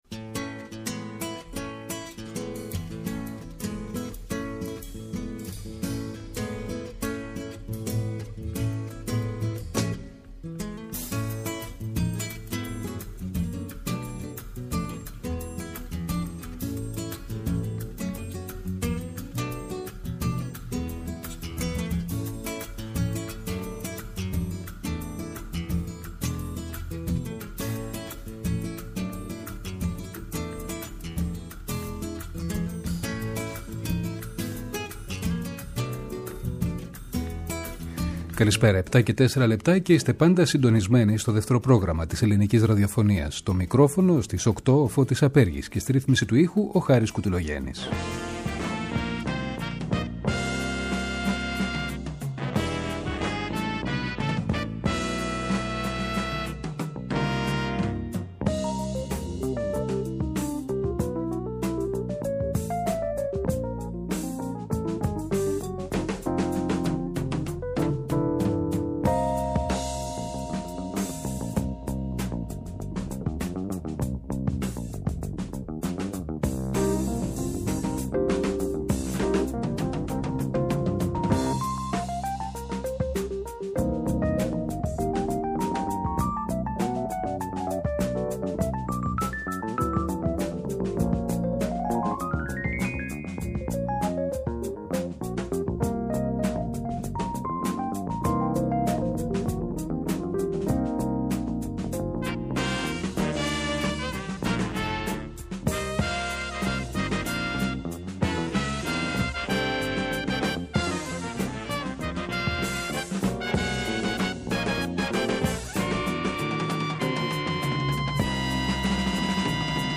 στο studio του Δεύτερου